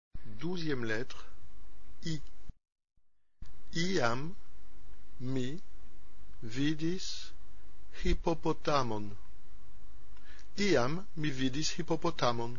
12 - I I
-2) la phrase prononcée lentement en séparant bien les mots,
-3) la phrase prononcée normalement.